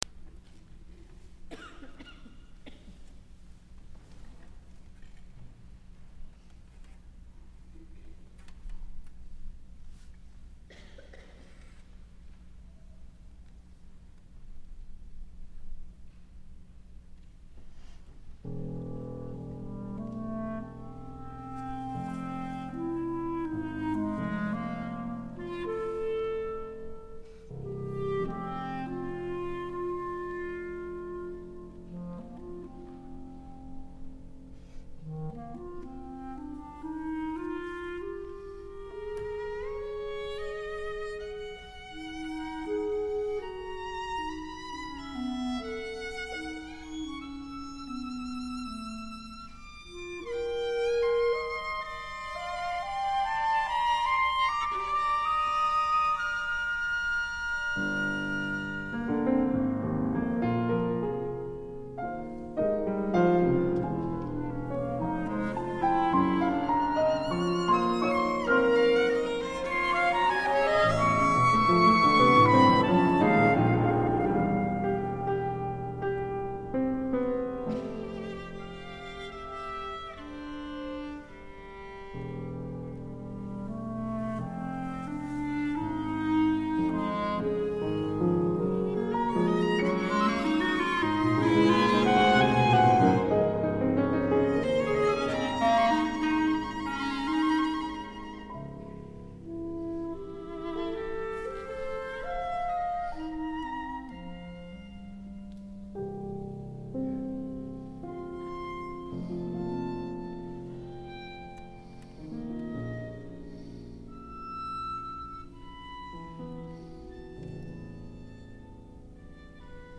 for Violin, Clarinet, Piano
world premiere performance